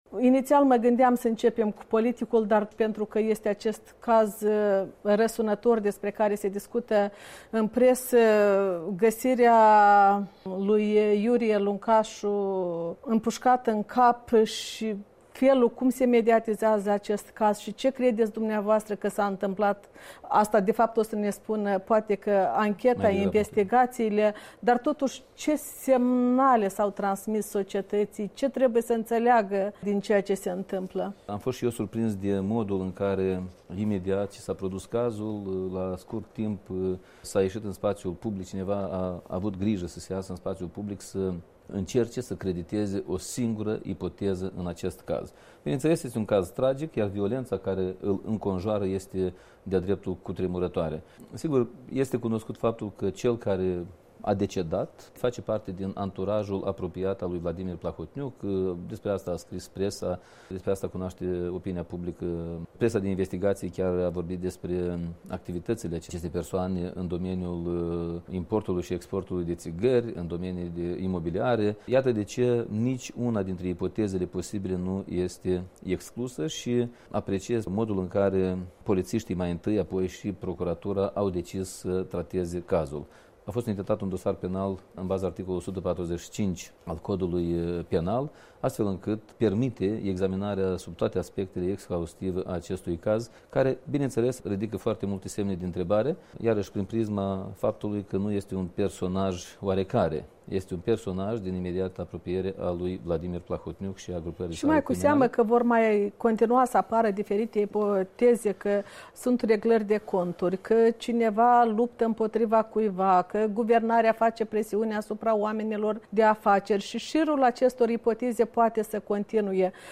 Interviu cu ministrul de interne Andrei Năstase